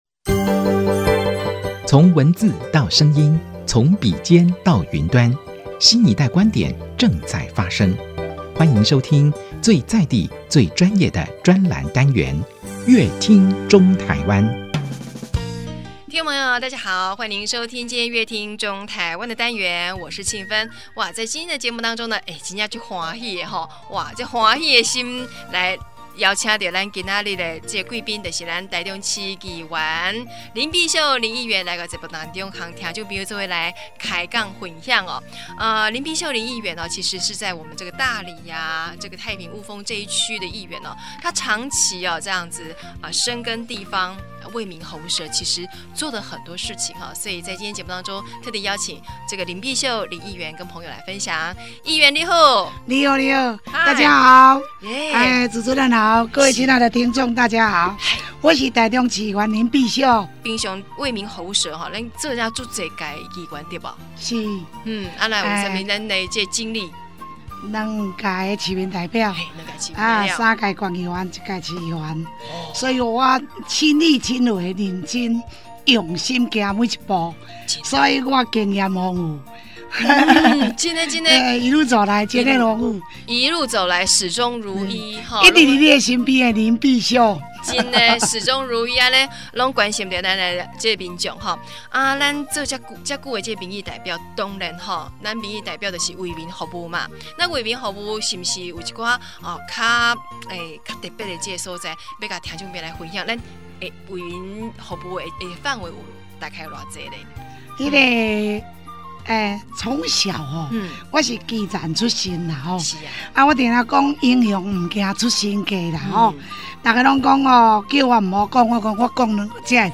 本集來賓：台中市議員林碧秀 本集主題：深耕地方 服務鄉親 本集內容： 從小在市場賣菜的林碧秀議員，天生熱心，見不得不好的事，也因為這一股熱心與熱情讓林碧秀一路從大里的市民代表、台中縣議員，做到現在的大台中市議員，將雞婆心的熱情個性完全表露無遺，照顧弱勢、新住民，更關心文化傳承，努力保存林家花園舊址，還自掏腰包增加設備、與公務機關協調，在大里為民眾打造機車考照場，造福市民，現在更希望將這一份服務熱忱繼續傳遞下去。